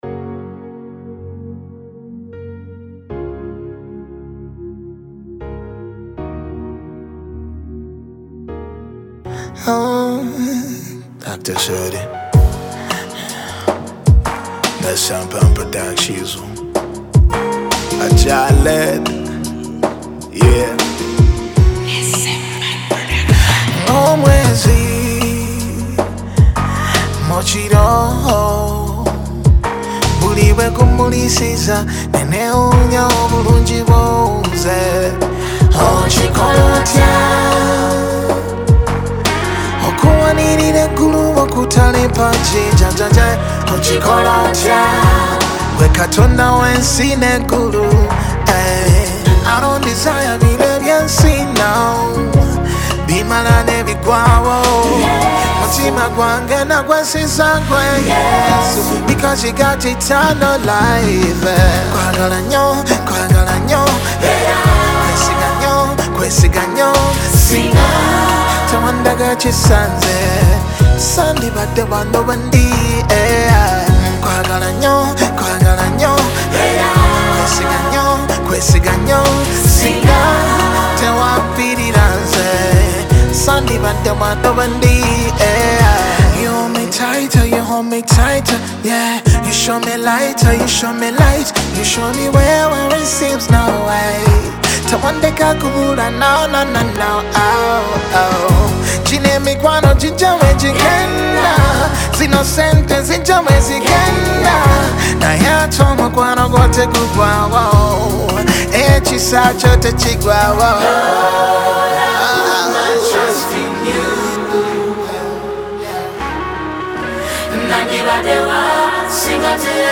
Ugandan Gospel singer